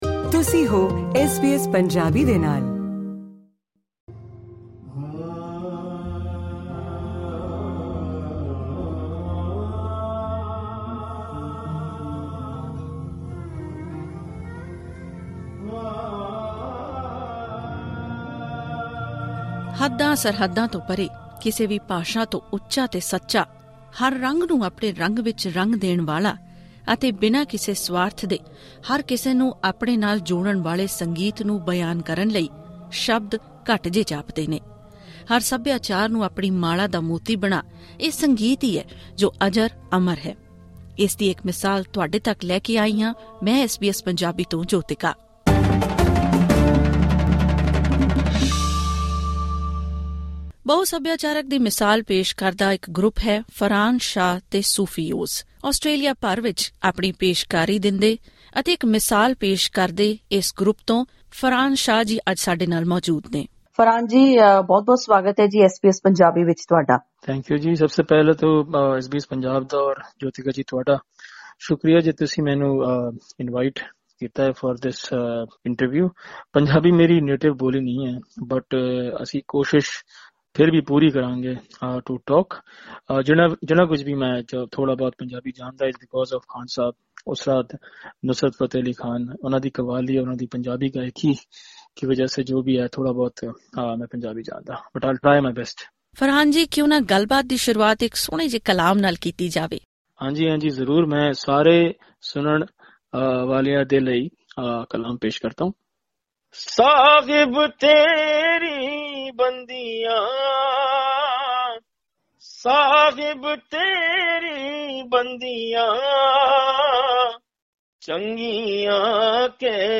ਹੋਰ ਵੇਰਵੇ ਲਈ ਸੁਣੋ ਇਹ ਆਡੀਓ ਇੰਟਰਵਿਊ...